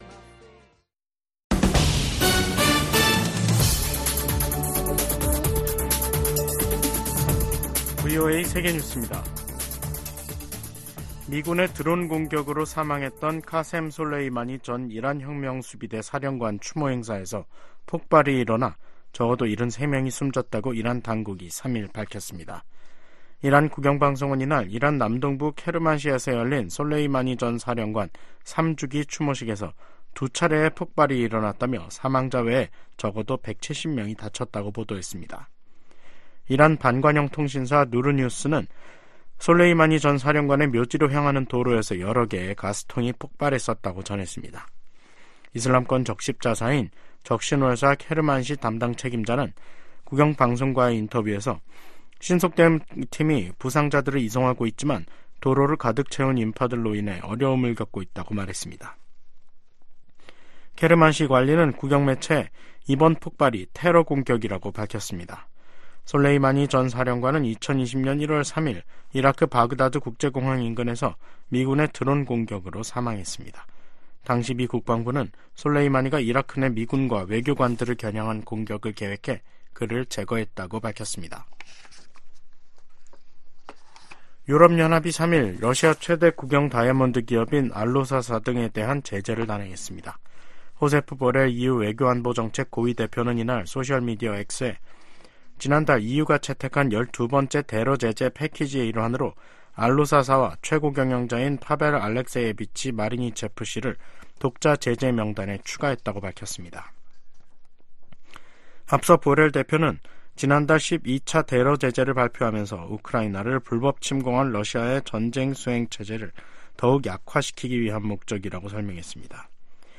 VOA 한국어 간판 뉴스 프로그램 '뉴스 투데이', 2024년 1월 3일 3부 방송입니다.